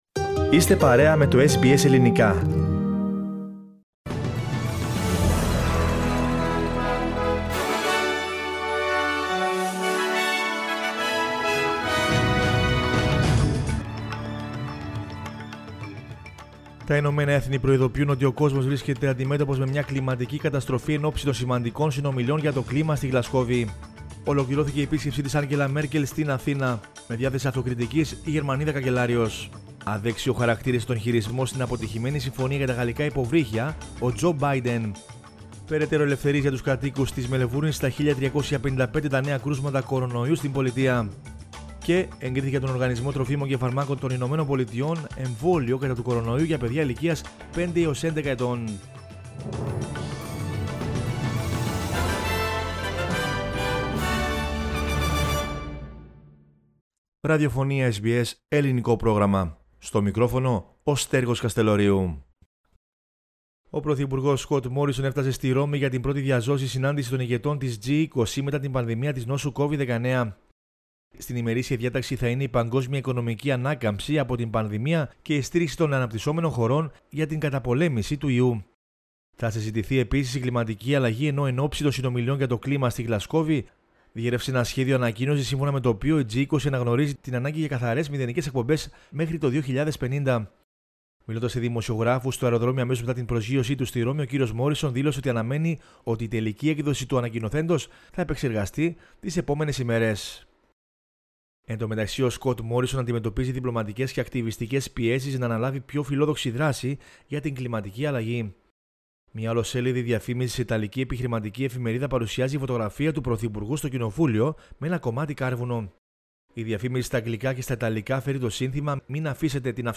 News in Greek from Australia, Greece, Cyprus and the world is the news bulletin of Saturday 30 October 2021.